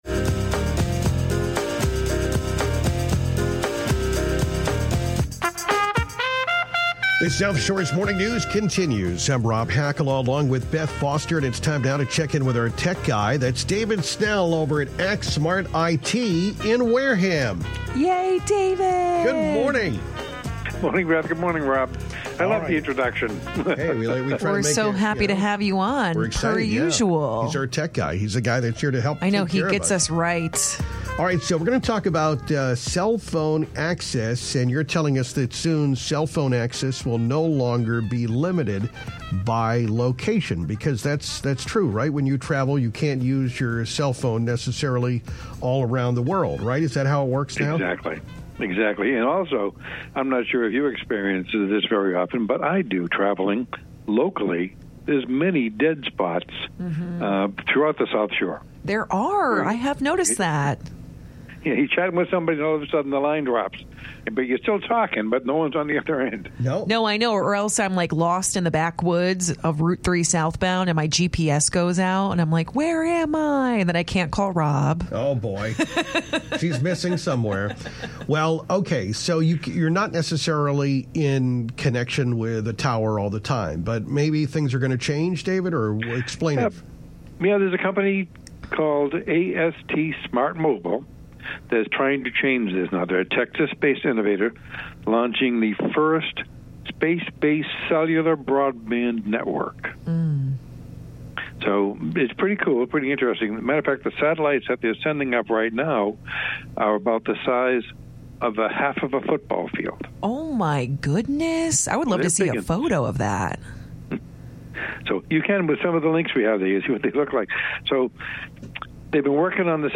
This article is correct but the show recording has the blooper!